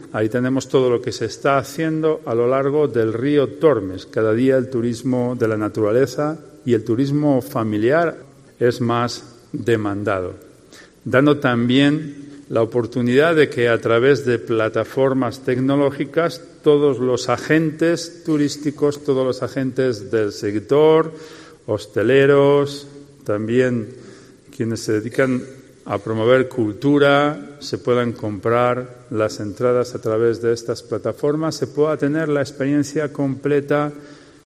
Carlos García Carbayo, alcalde de Salamanca, presenta el Plan de Sostenibilidad Turística de Salamanca